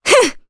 Maria-Vox_Attack1_jp.wav